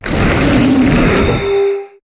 Cri d'Ama-Ama dans Pokémon HOME.